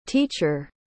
Teacher em Inglês: Significado, Tradução e Pronúncia
Teacher.mp3